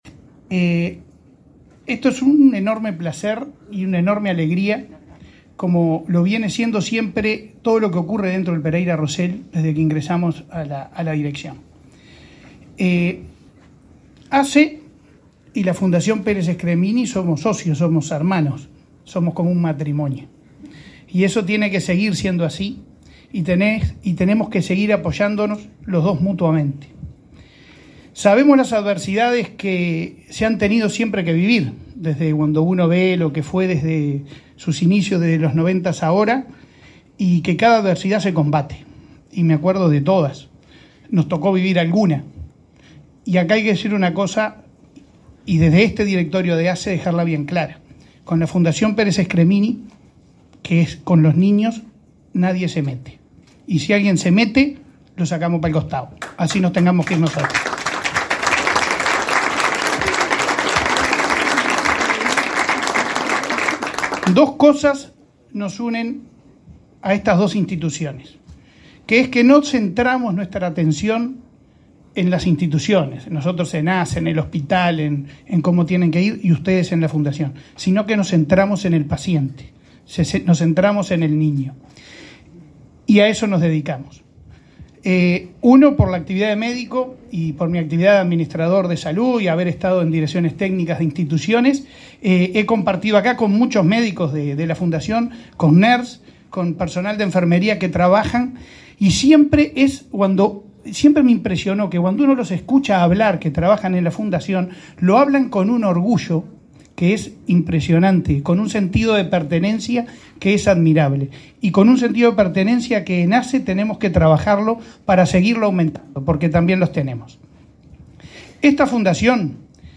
Palabras del presidente de ASSE y del ministro de Salud Pública
El presidente de ASSE, Leonardo Cipriani, y el ministro de Salud Pública, Daniel Salinas, participaron este martes 15 en la inauguración de obras en